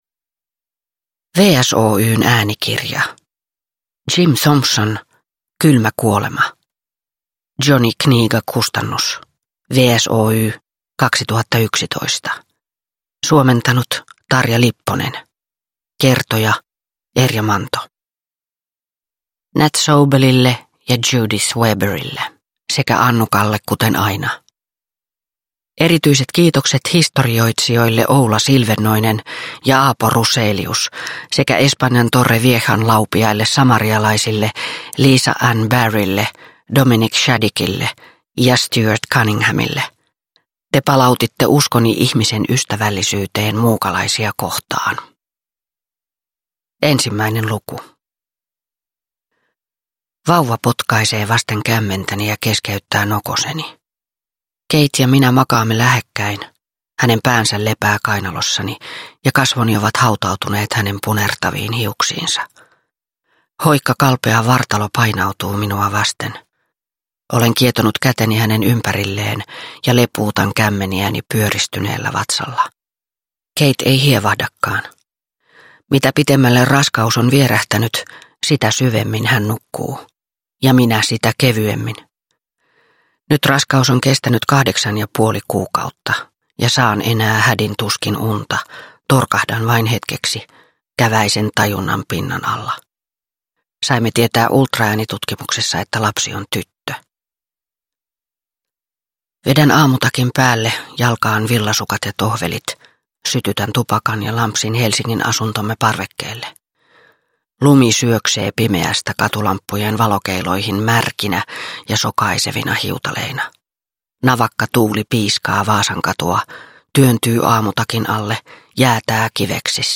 omaksuu suvereenisti Kari Vaaran miehekkään kertojanäänen, joka on sekä karu että syvästi inhimillinen.